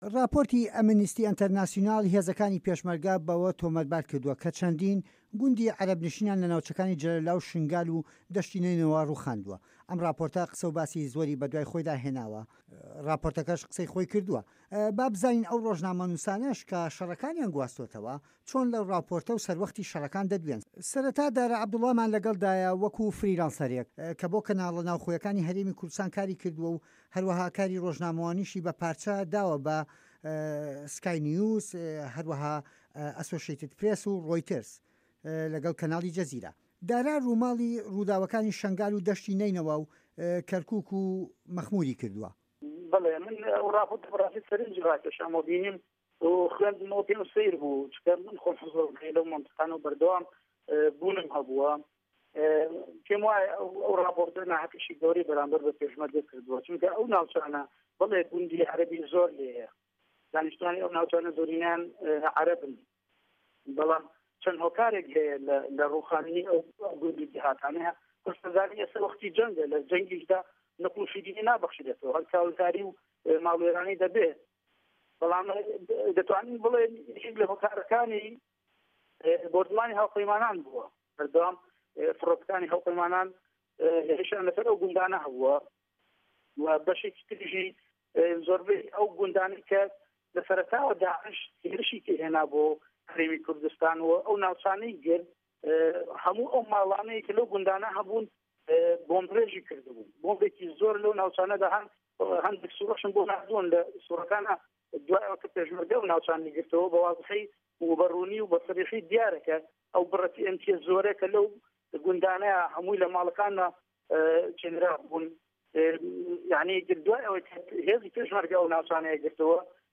هه‌رێمه‌ کوردیـیه‌کان - گفتوگۆکان
دوو رۆژنامه‌نووس وتوێژیان له‌گه‌ڵدا کراوه‌ که‌ له‌ سه‌روه‌ختی ئه‌و شه‌ڕانه‌ی سنوره‌کانی شه‌نگال و ده‌شتی نه‌ینه‌وا و مه‌خمور و جه‌له‌ولا و سنوری گه‌رمیان، خۆیان و چاوی کامێراکانیان له‌وێ بوون.